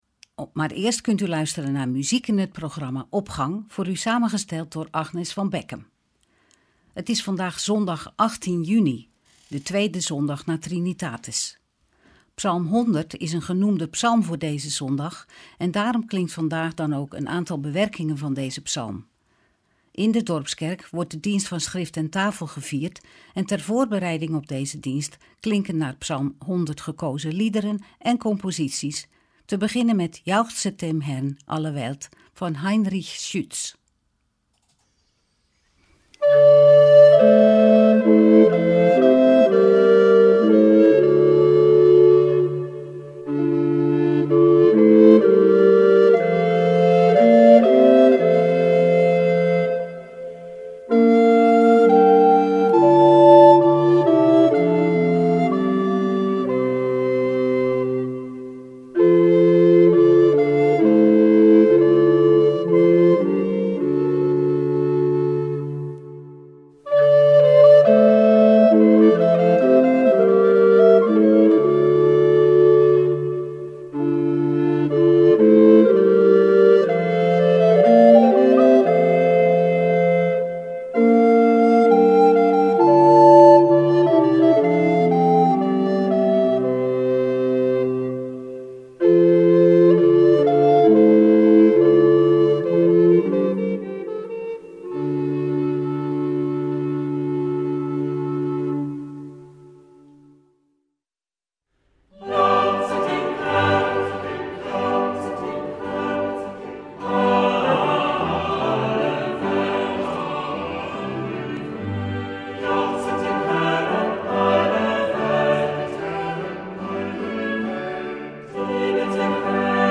instrumentaal